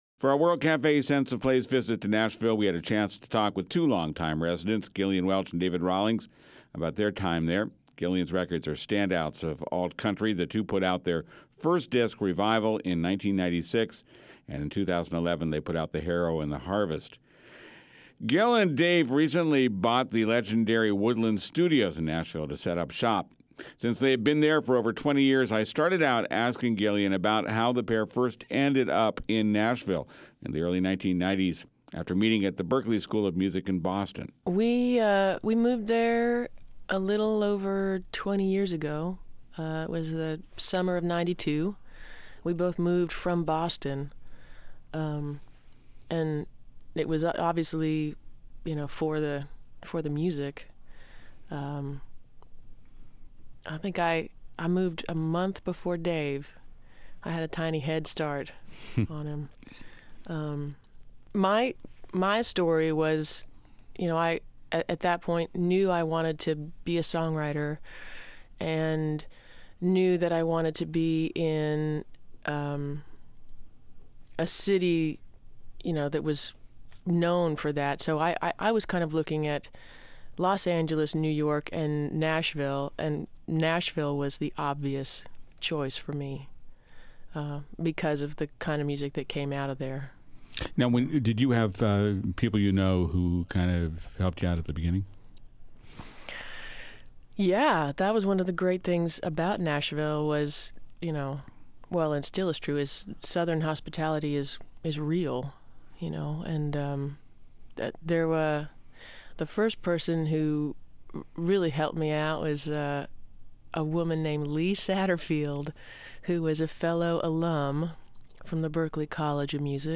Listen to an extended conversation with the country-folk singers about how the city's changed over the past 20 years.